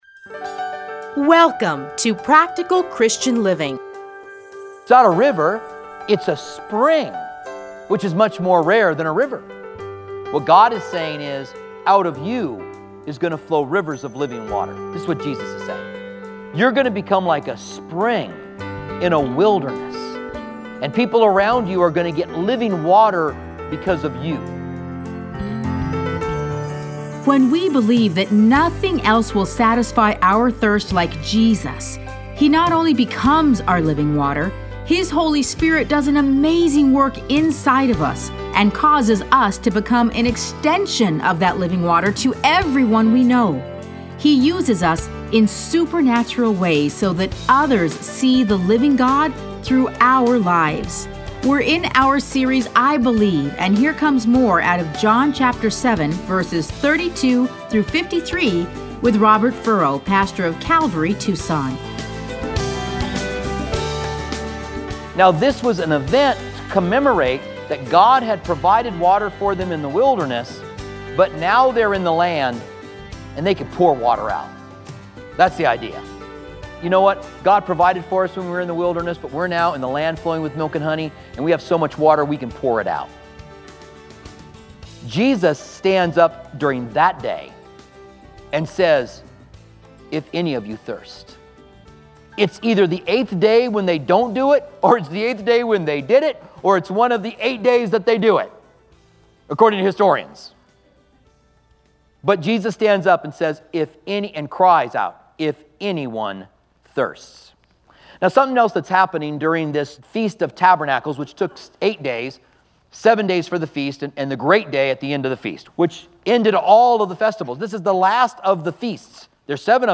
Listen to a teaching from John 7:32-53.